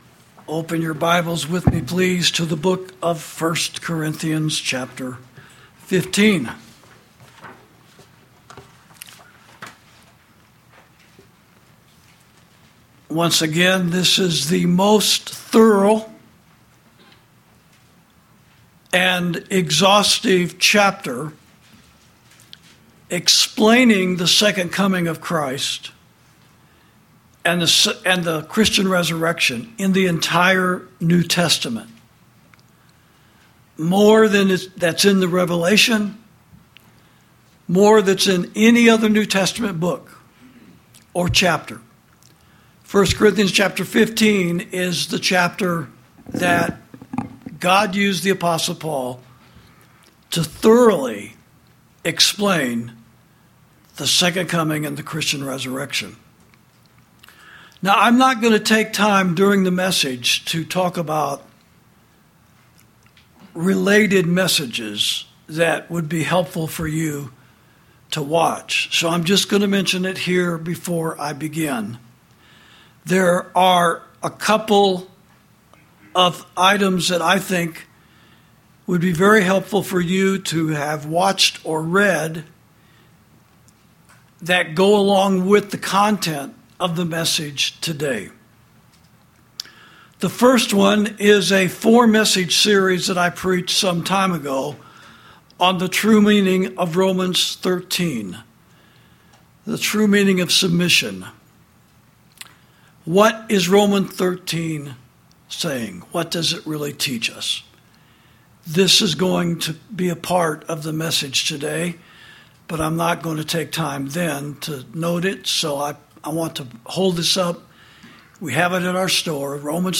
Sermons > The Christian Resurrection Confirms And Completes The Present Heavenly Reign Of Christ (Supplemental Prophecy Message Number Ten—Part Two)
during the service at Liberty Fellowship.